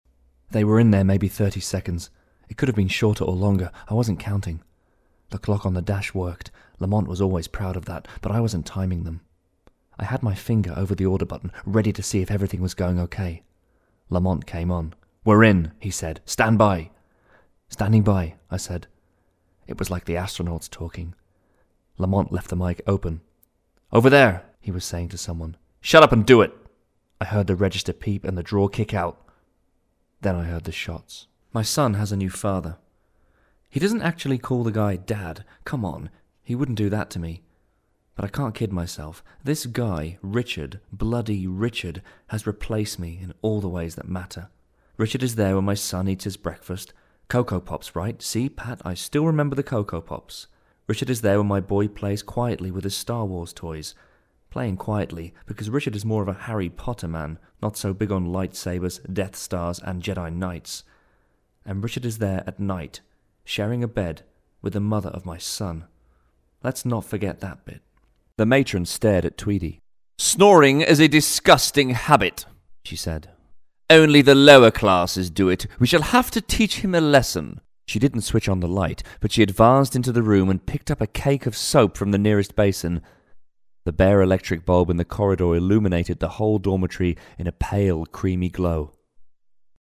Sprecher englisch (usa) Dialekte - All UK regions, most European countries, USA, Deep South, New York, Australian, Hebrew, Indian, Russian, Scottish, Welsh, Irish
englisch (uk)
Sprechprobe: Industrie (Muttersprache):